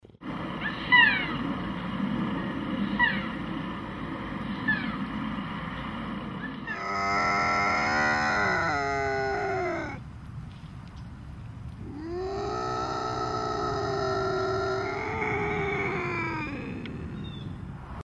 Bobcat
Voice
Bobcats rarely meow like domestic cats, but chortle and emit birdlike chirps. During mating season, their vocalizations resemble that of a screaming domestic alley cat.
bobcat-call.mp3